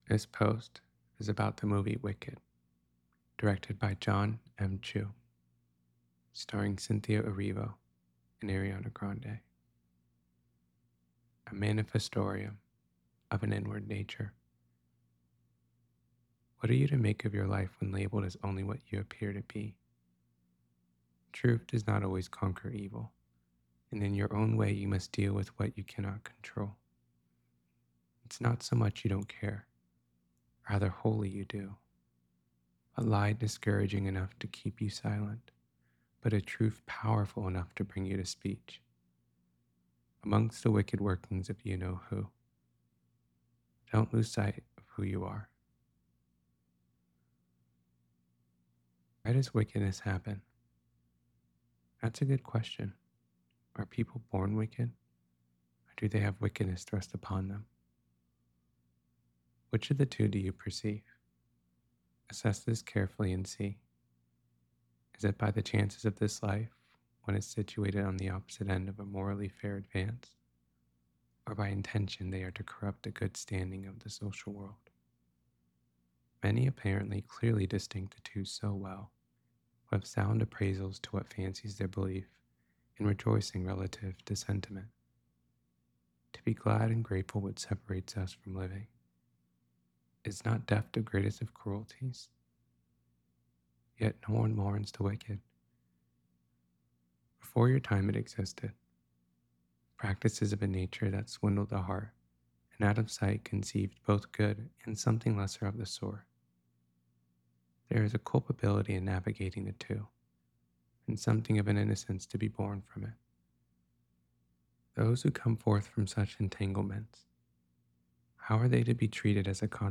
wicked-to-know-a-story-reading.mp3